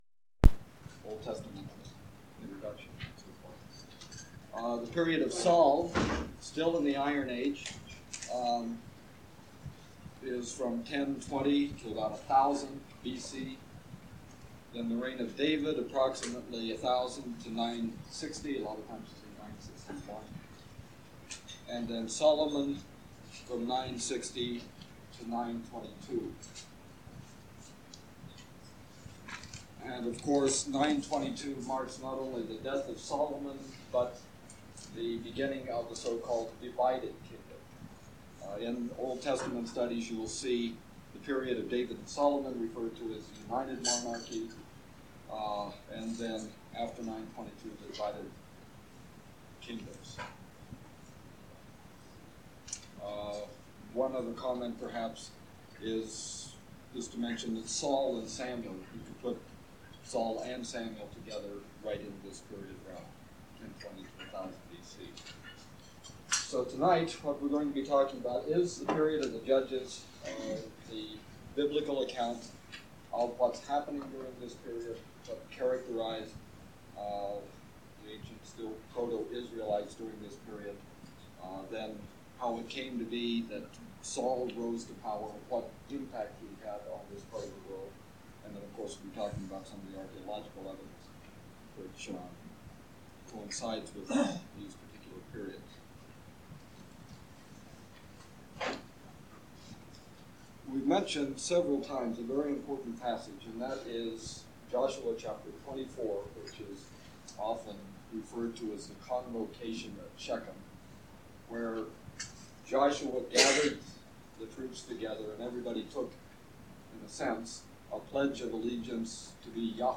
Archaeology of Jordan and Biblical History - Lecture 13
Format en audiocassette ID from Starchive 417992 Tag en Excavations (Archaeology) -- Jordan en Bible -- Antiquities en Archaeology Item sets ACOR Audio-visual Collection Media Arch_Bible_13_access.mp3